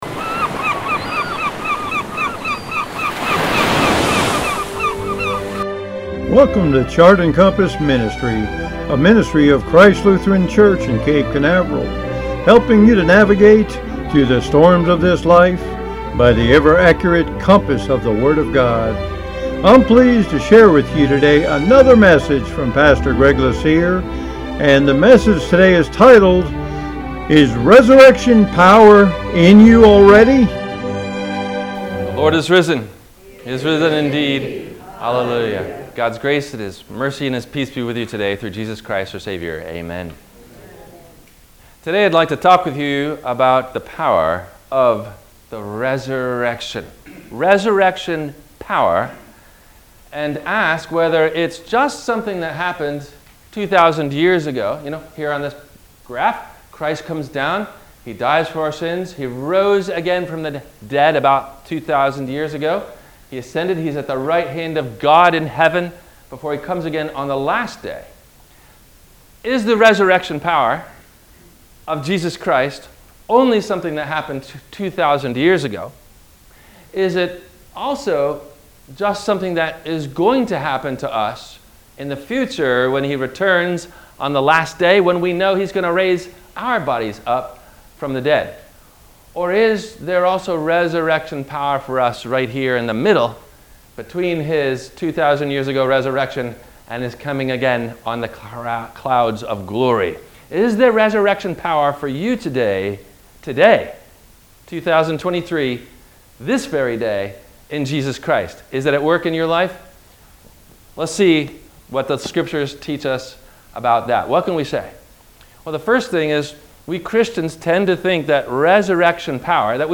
Is Resurrection Power In You Already? – WMIE Radio Sermon – April 27 2026 - Christ Lutheran Cape Canaveral
Including Intro and closing Plug.